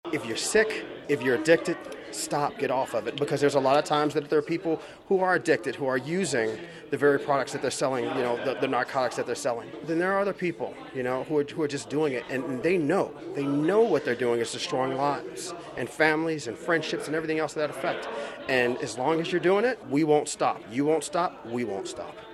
On Wednesday, leadership from RCPD, the Kansas Highway Patrol and Drug Enforcement Administration gathered at the Riley County Attorney’s Office building to announce four more arrests as part of their ongoing investigation.